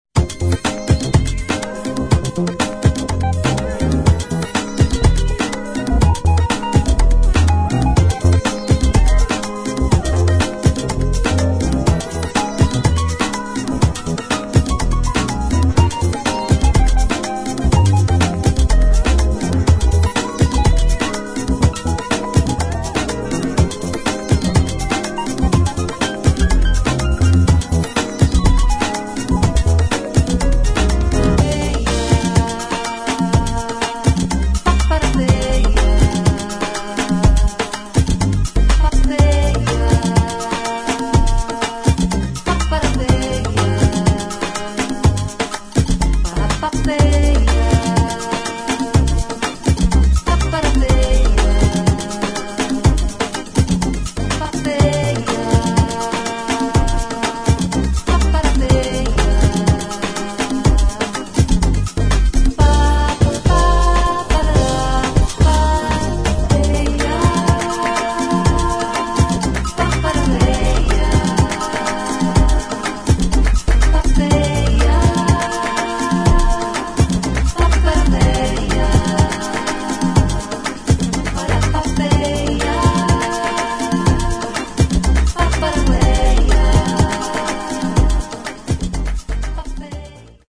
[ FUTURE JAZZ | LATIN | BROKEN BEAT ]